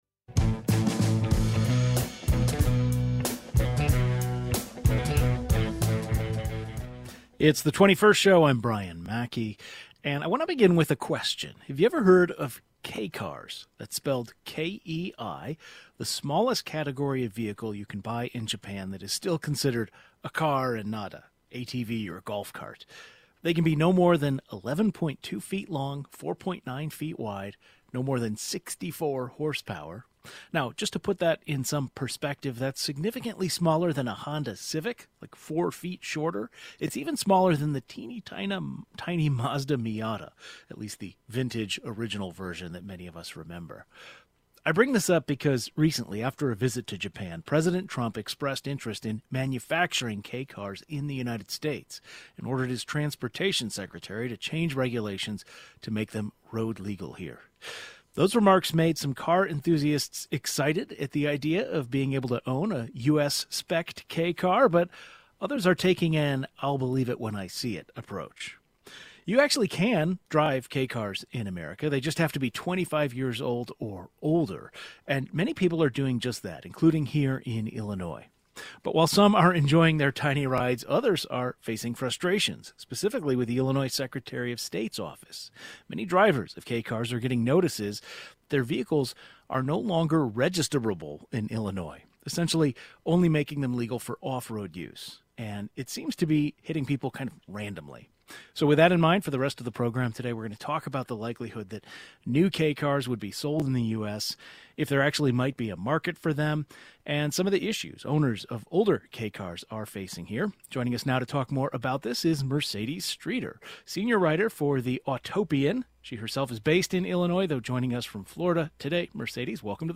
A journalist who covers the automotive industry and a car importer discuss Trump's proposal and if there is market for Kei cars in the U.S. The 21st Show is Illinois' statewide weekday public radio talk show, connecting Illinois and bringing you the news, culture, and stories that matter to the 21st state.